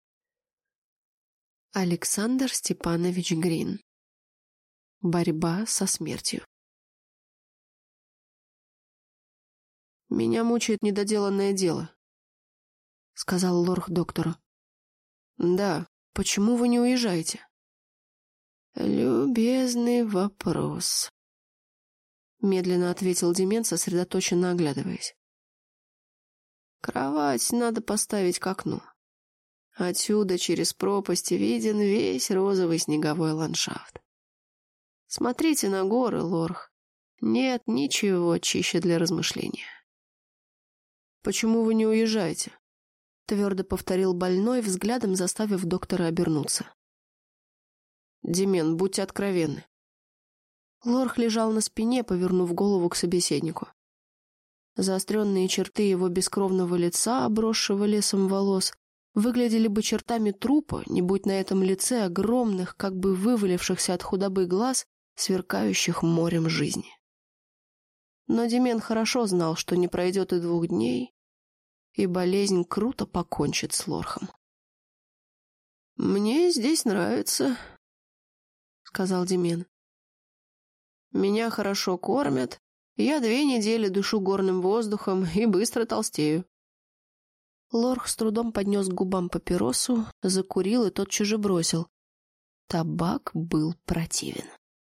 Аудиокнига Борьба со смертью | Библиотека аудиокниг